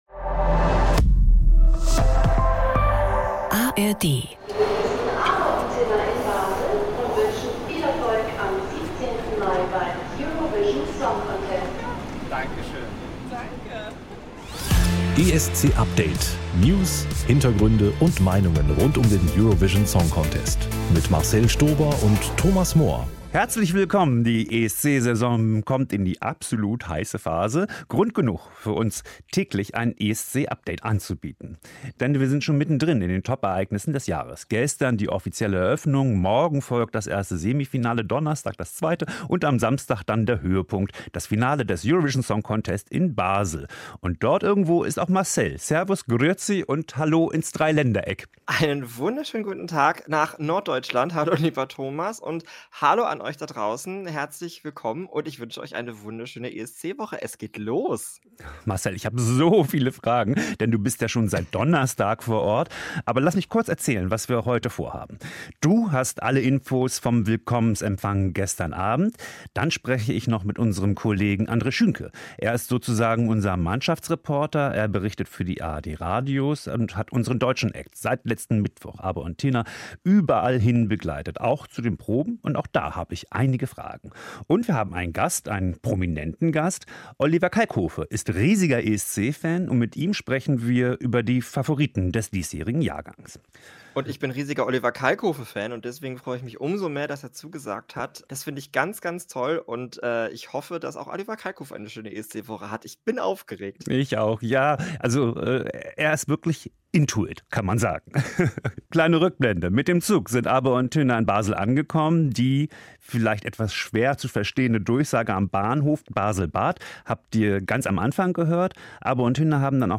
Darüber und über die ersten Tage von Abor & Tynna und ihre Proben in Basel, sprechen wir in der ersten täglichen Folge von ESC Update direkt von vor Ort.